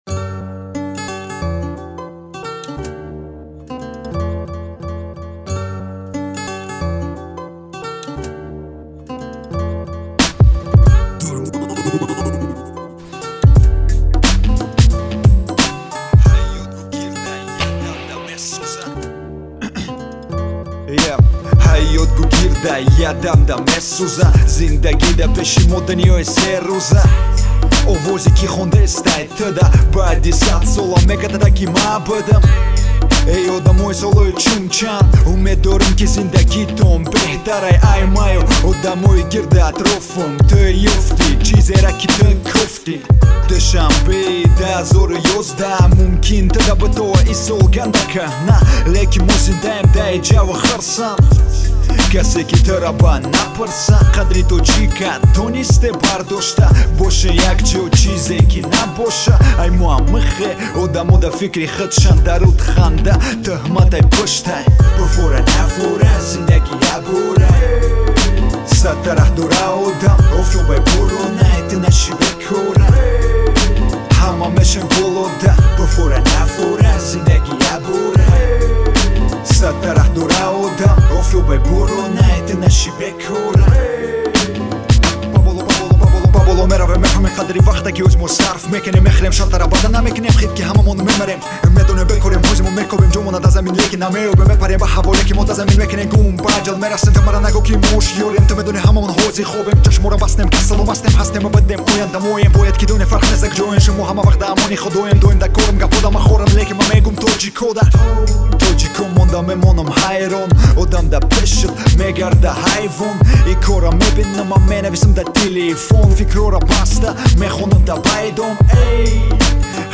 Главная » Каталог mp3 » Рэп / HIP HOP » Tajik Rap